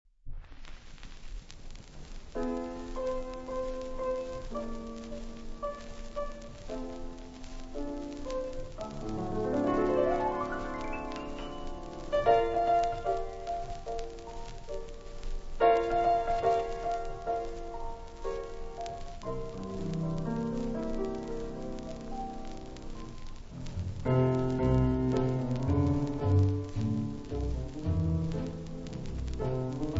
• fantasie
• foxtrott
• potpourri